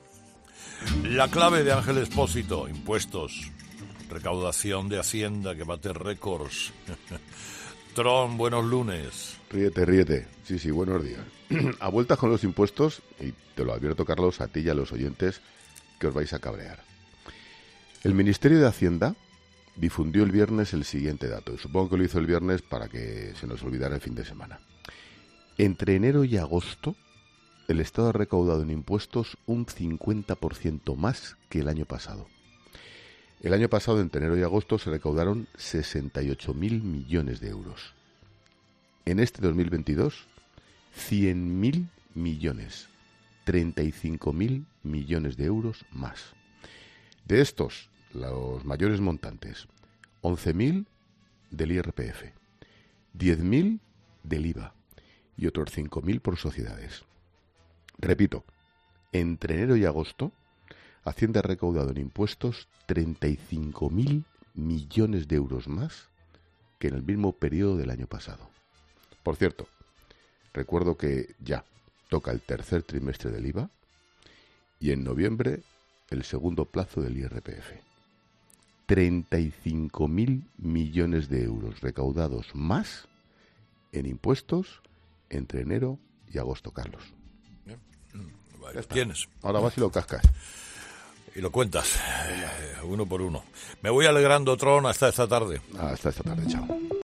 "A vueltas con los impuestos y advierto a los oyentes que se van a enfadar", así comenzaba Ángel Expósito este lunes 'El paseíllo del Tron' en Herrera en COPE.